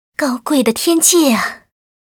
文件 文件历史 文件用途 全域文件用途 Erze_amb_07.ogg （Ogg Vorbis声音文件，长度2.0秒，90 kbps，文件大小：23 KB） 源地址:地下城与勇士游戏语音 文件历史 点击某个日期/时间查看对应时刻的文件。